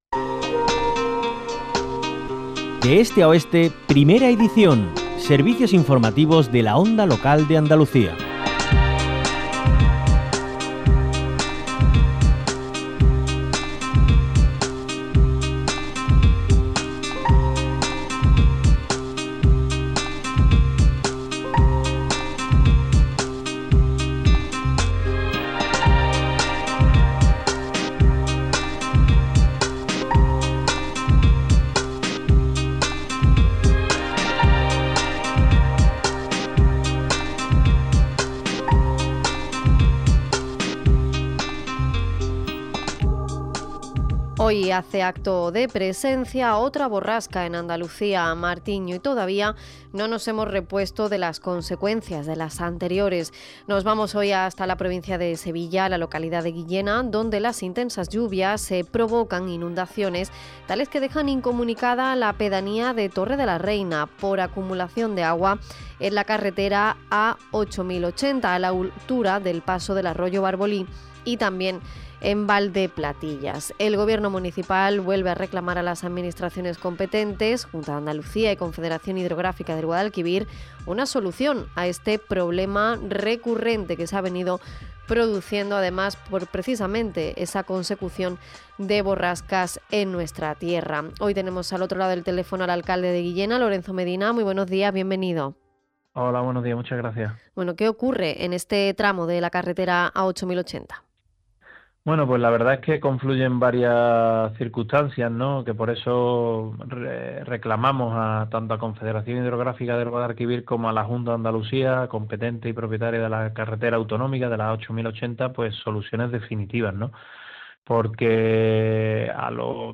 LORENZO MEDINA – ALCALDE DE GUILLENA